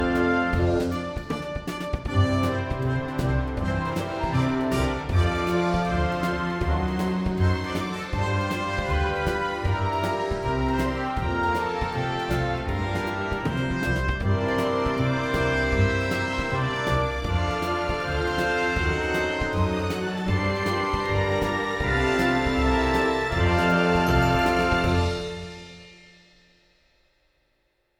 Три раза одна труба в одну точку сыграла три ноты. Под голым альтивербом звучат лучше берлинов в деке.